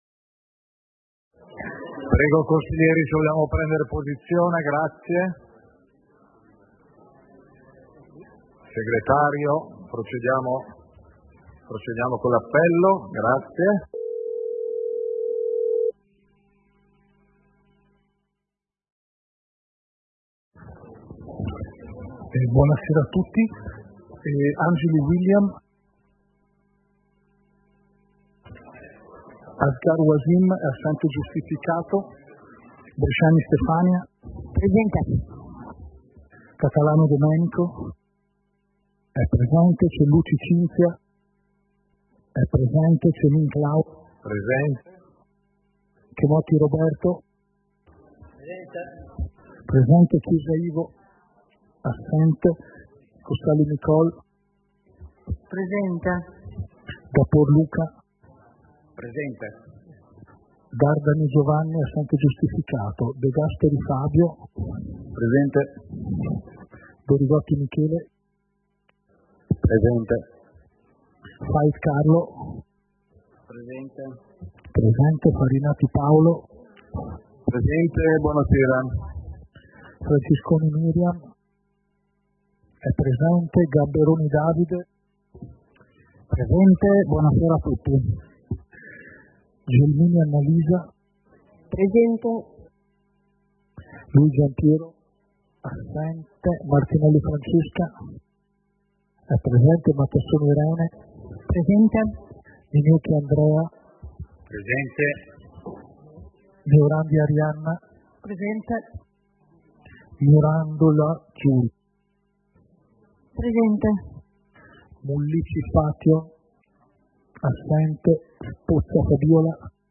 Seduta del consiglio comunale - 22.12.2025